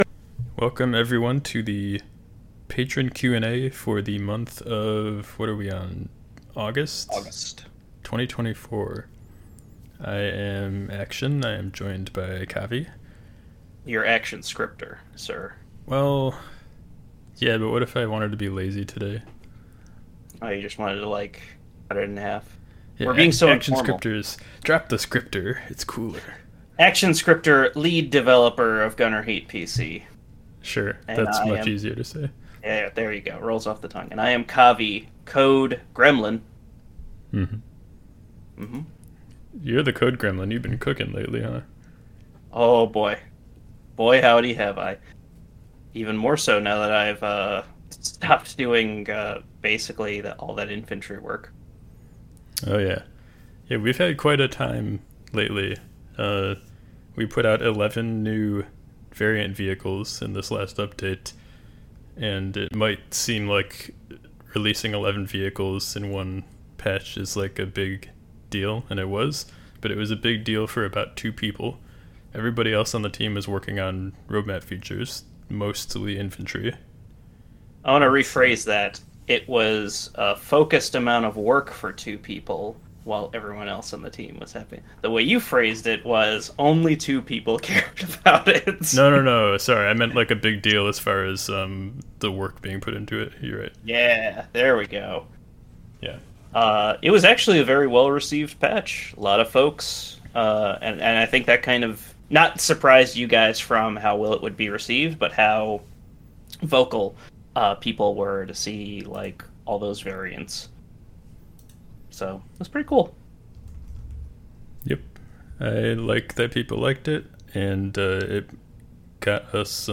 This is the recording from the latest Q&A session for anyone who couldn't make it.
The Q&A sessions are held using the patron stage there.